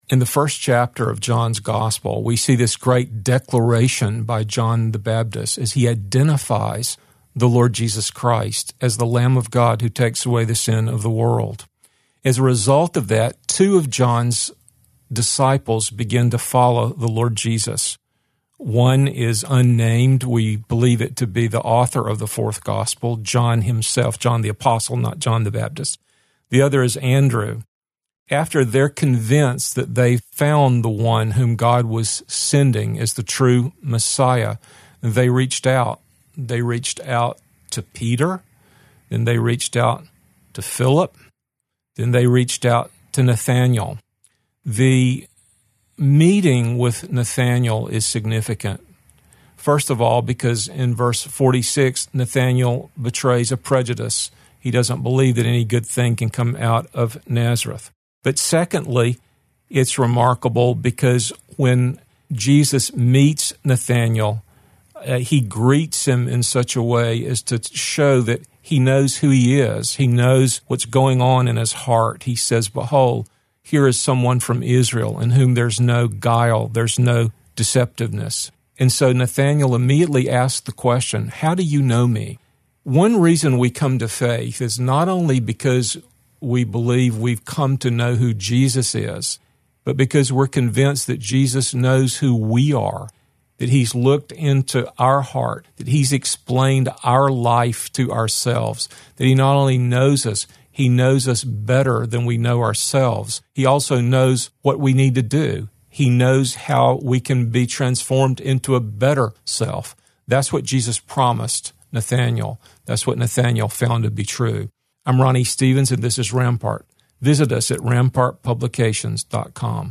two minute radio broadcasts